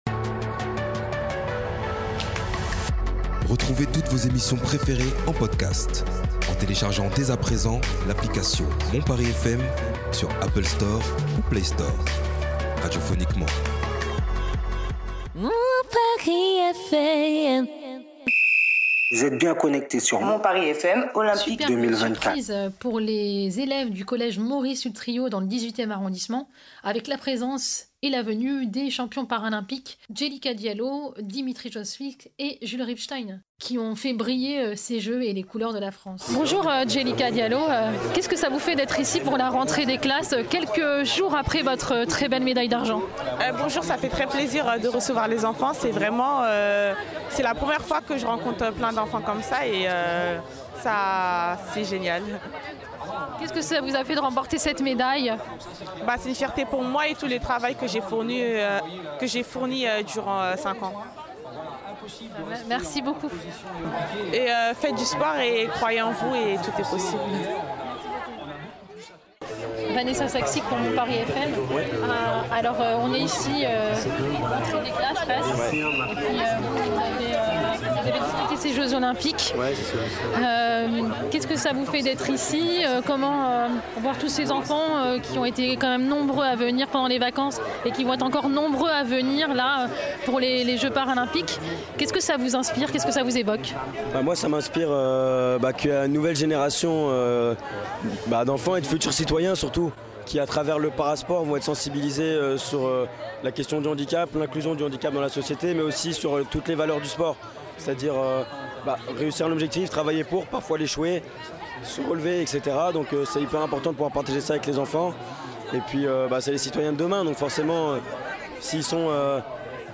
Au micro de Mon Paris FM, Tony Estanguet, Président de Paris 2024, Amélie Oudéa Castéra, Ministre des Sports et des JOP de Paris 2024 et Nicole Belloubet, Ministre de l’Education Nationale.